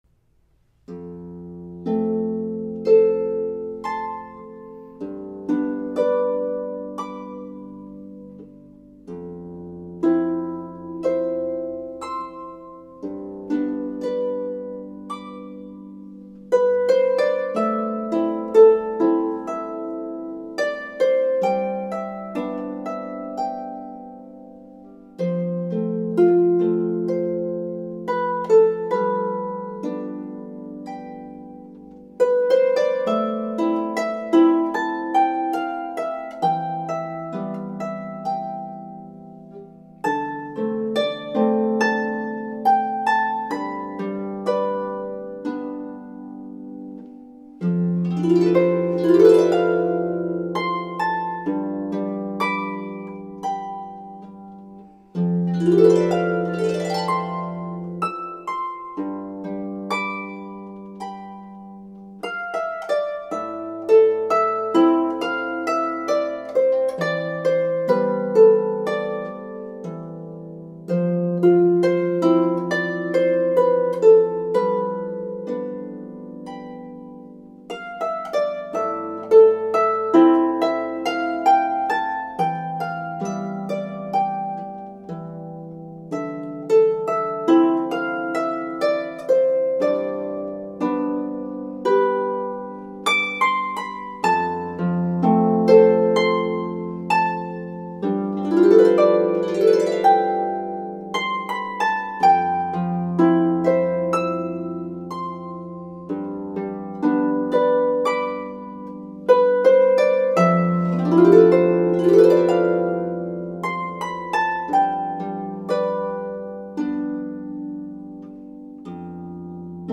is for two or three lever or pedal harps